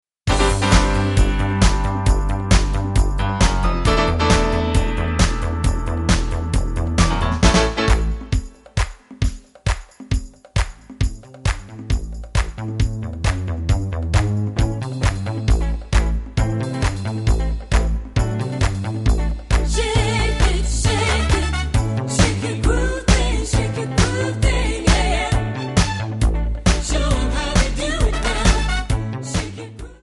Backing track files: Disco (180)